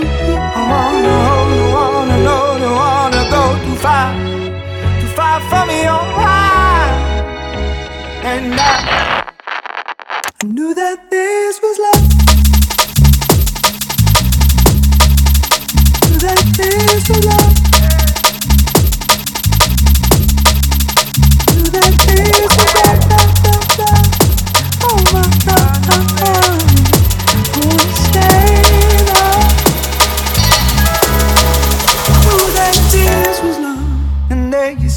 Жанр: Танцевальные / Электроника
Dance, Electronic